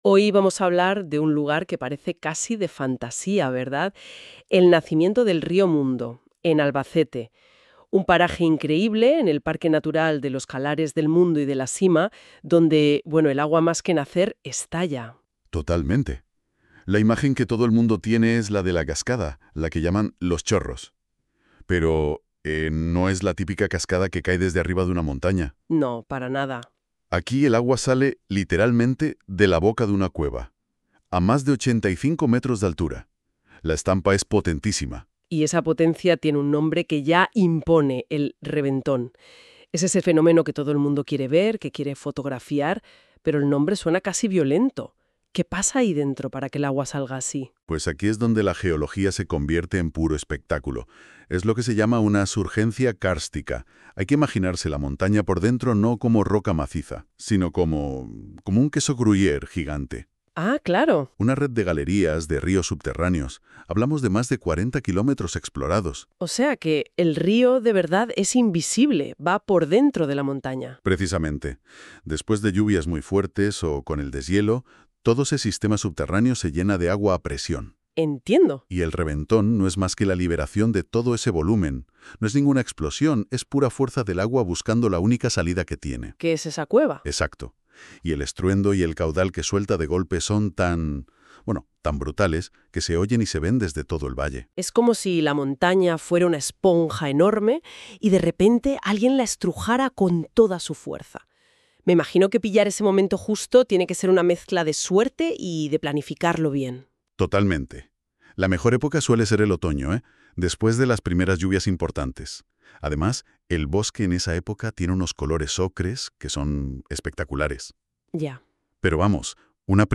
El_espectacular_reventon_del_rio_Mundo.mp3